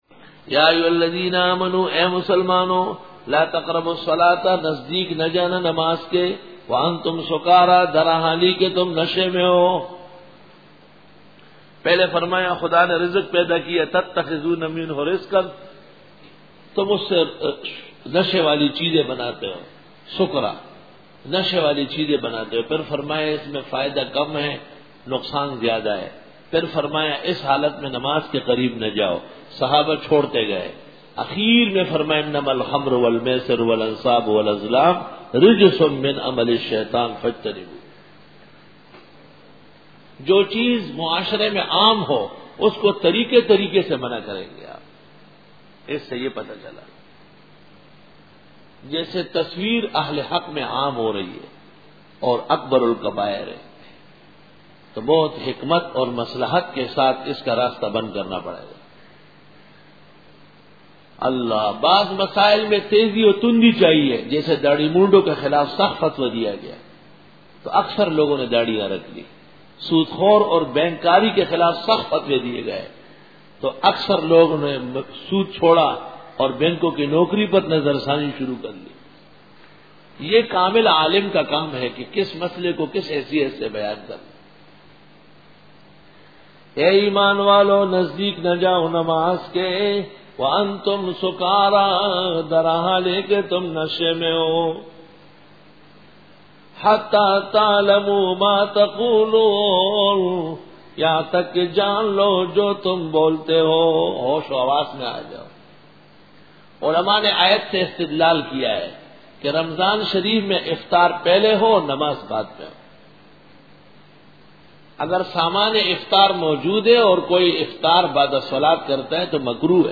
Dora-e-Tafseer 2004